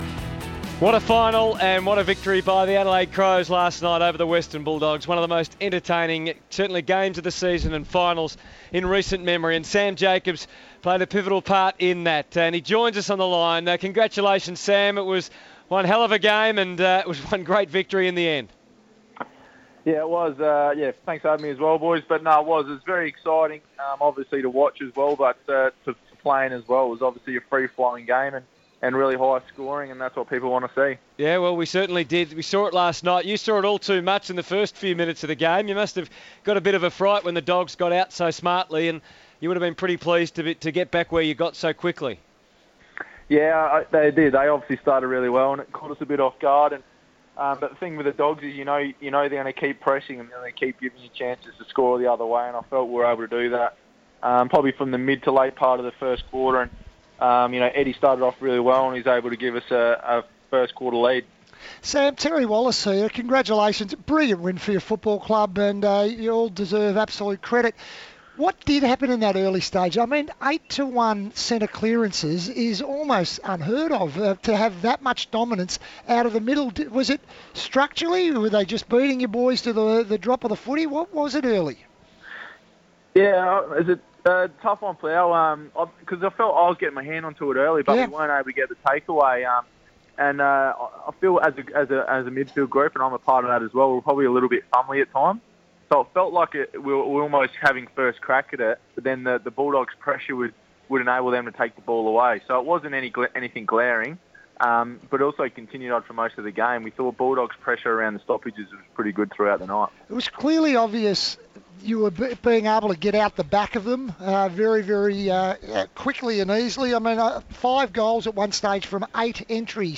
Sam Jacobs on the phone
Adelaide's ruckman Sam Jacobs joined our commentary team to discuss the Crows' win against the Western Bulldogs.